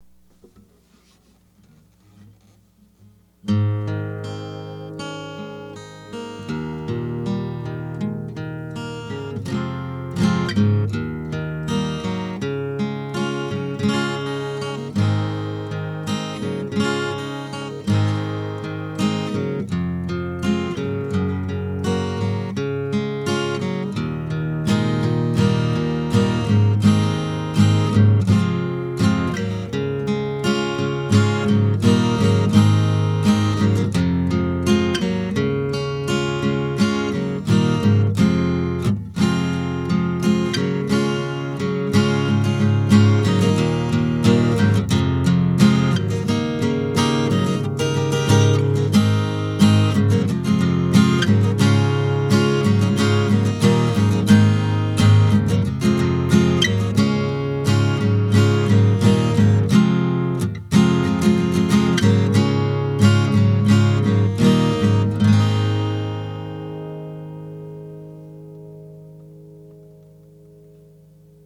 The guitar sounds pretty good - I'd like a richer low end, but it still sounds quite acceptable, and the action came out quite nice.
real audio file of some strumming on the completed guitar is also available. Note that it's a CD quality file, and so is pretty big - too large for a modem to handle playing in real time, so you'd probably have to download it to your local hard disk and play it from there.